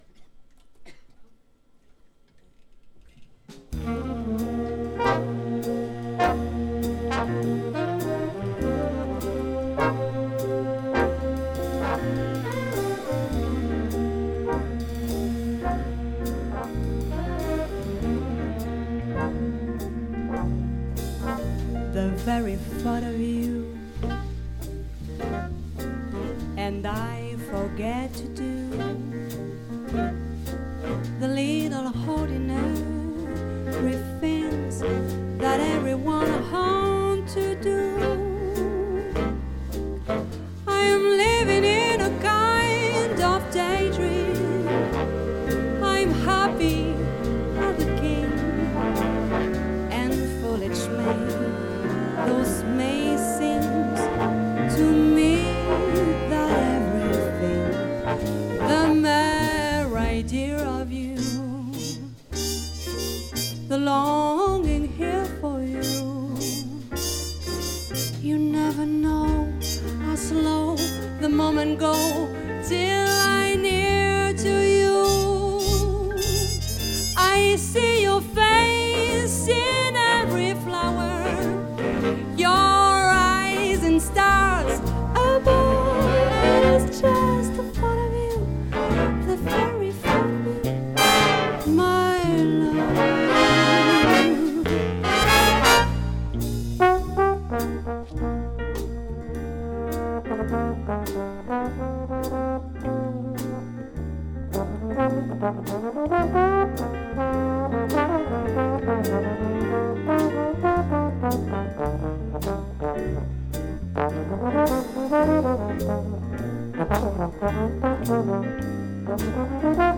Le Big Band de Pertuis lors de la 19ème édition du Festival
au trombone
à la trompette